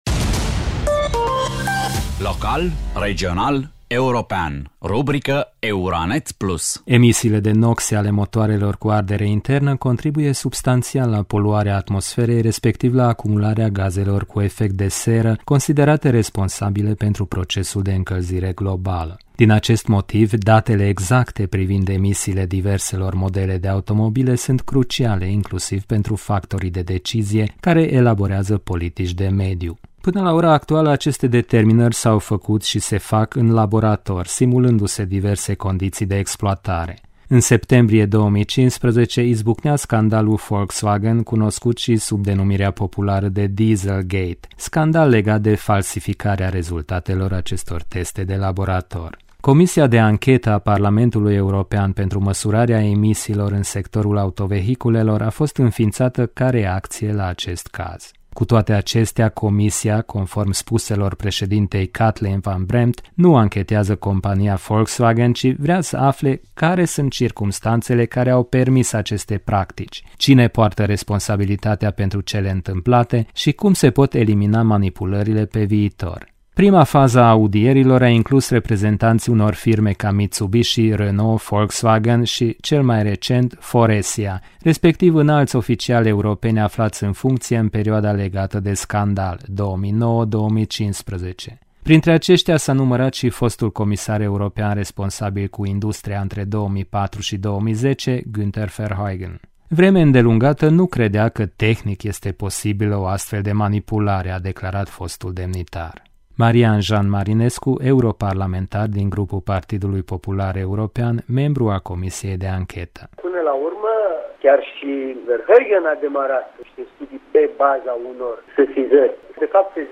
Marian – Jean Marinescu, europarlamentar din Grupul Partidului Popular European, membru al comisiei de anchetă: